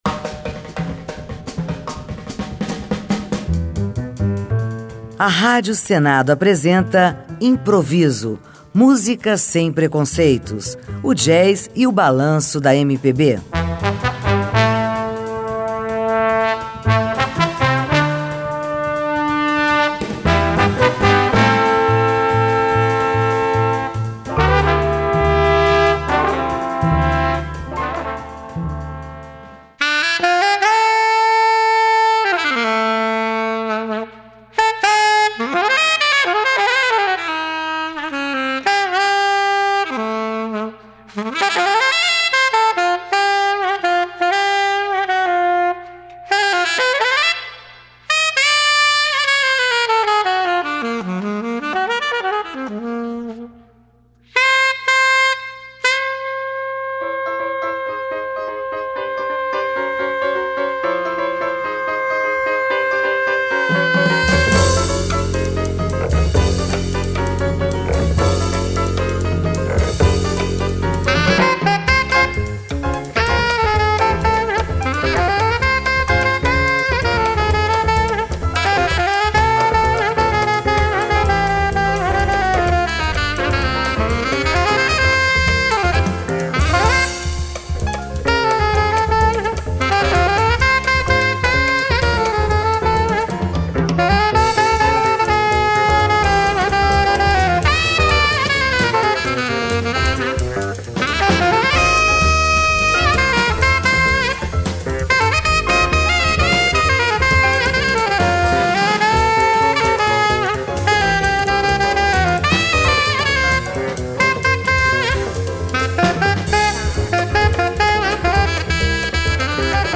O Jazz do Brasil, na Rádio Senado
Programa semanal sobre a música instrumental brasileira